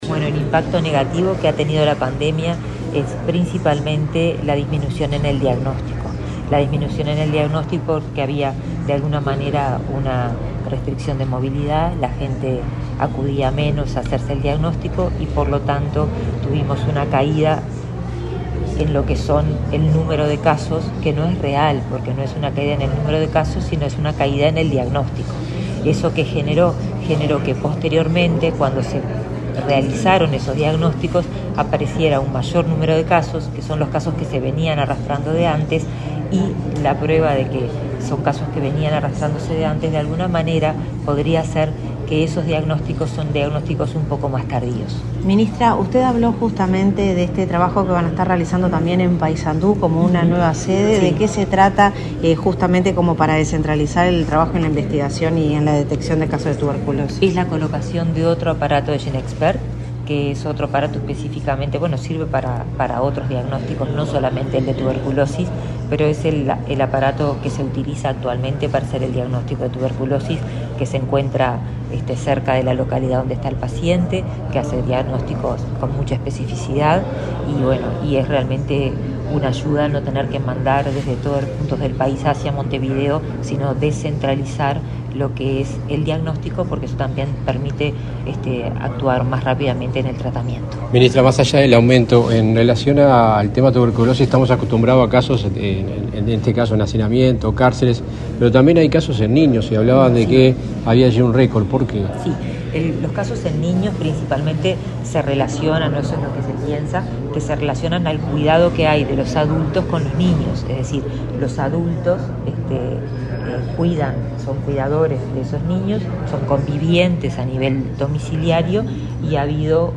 Declaraciones a la prensa de la ministra de Salud Pública, Karina Rando
Declaraciones a la prensa de la ministra de Salud Pública, Karina Rando 28/03/2023 Compartir Facebook X Copiar enlace WhatsApp LinkedIn Tras participar en el acto organizado por la Comisión Honoraria de Lucha Antituberculosa y Enfermedades Prevalentes, este 28 de marzo, con motivo del Día Mundial de la Tuberculosis, la ministra Karina Rando realizó declaraciones a la prensa.
Rando prensa.mp3